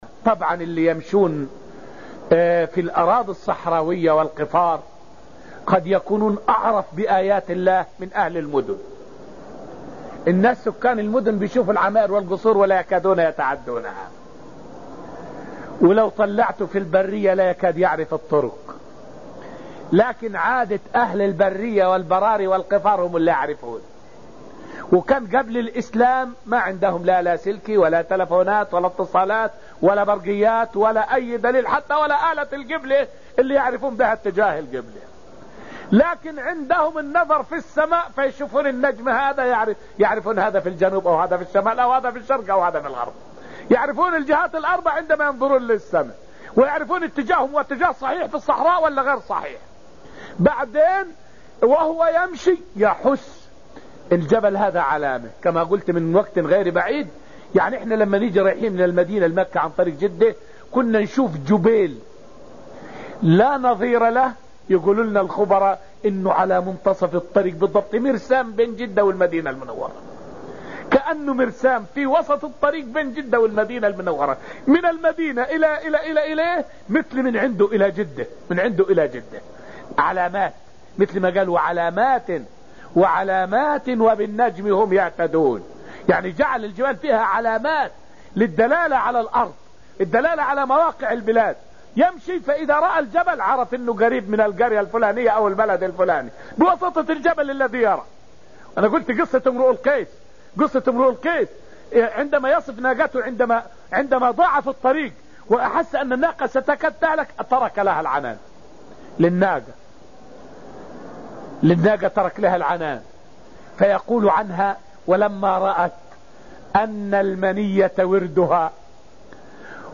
الذاريات تفسير تفسير القرآن دروس المسجد النبوي
فائدة من الدرس السادس من دروس تفسير سورة الذاريات والتي ألقيت في المسجد النبوي الشريف حول بيان أن أهل البوادي أعرف بآيات الله في الأرض من أهل المدن.